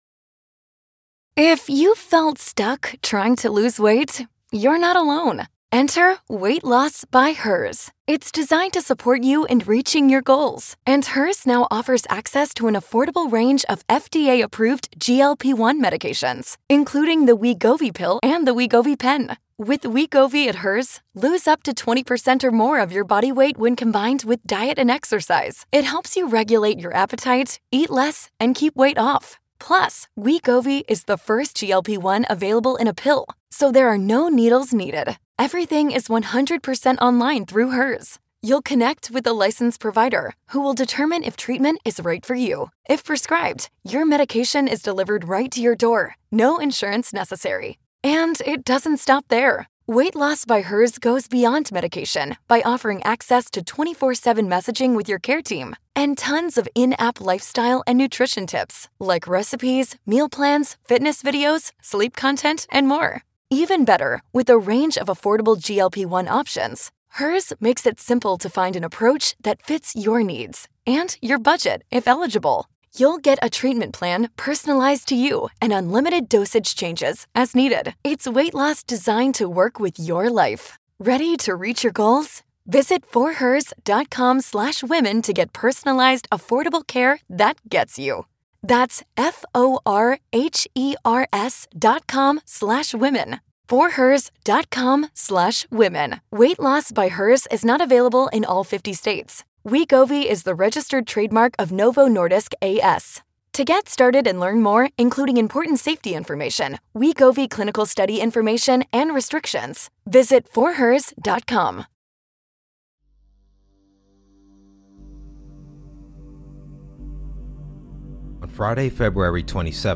it blends unsettling true stories, paranormal encounters, and mysterious passings with immersive sound design and atmospheric storytelling to make every episode feel like a late-night campfire tale you can’t turn off.